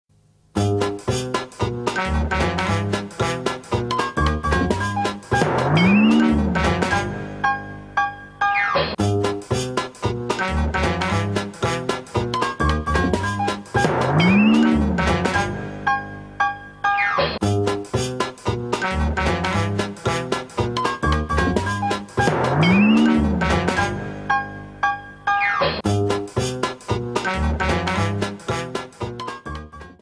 инструментальные
(Instrumental)